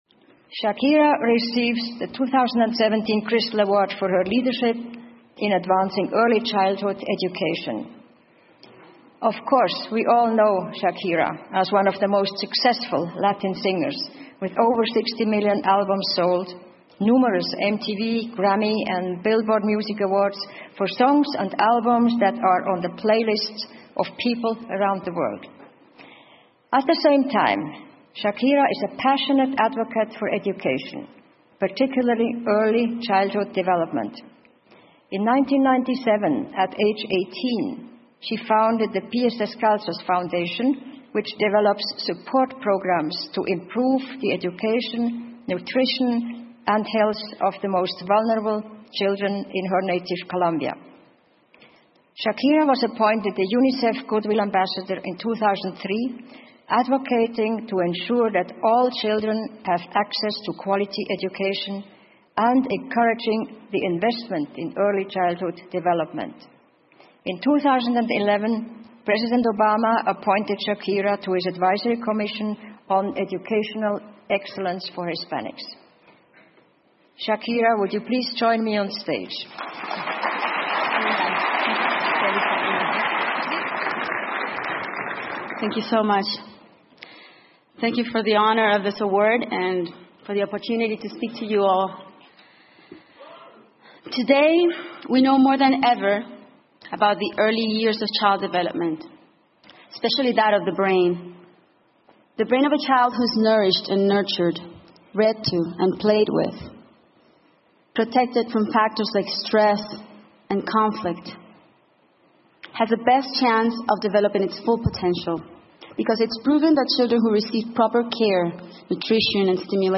英文演讲录 夏奇拉：关注儿童发展(1) 听力文件下载—在线英语听力室